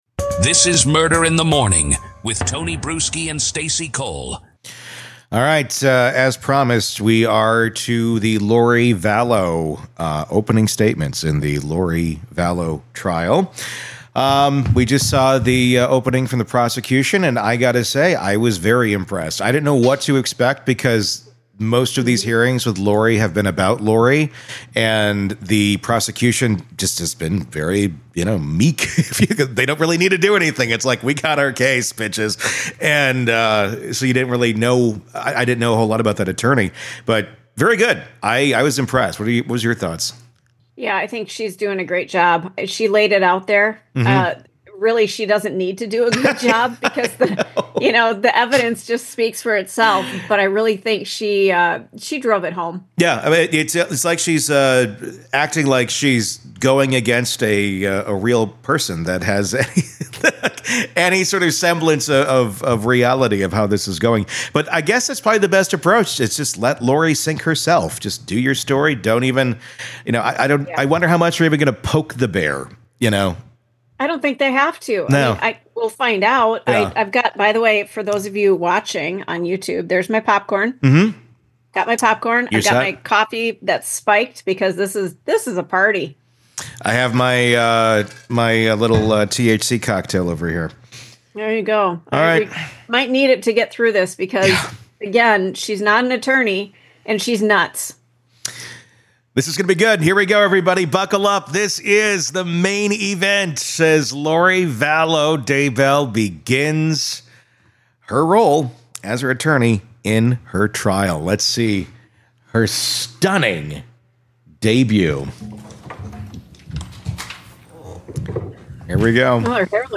Lori Vallow Daybell DEBUT As Her Own Attorney, Defense Opening Statements In Full